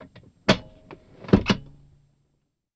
BMW Parking Brake Release With Button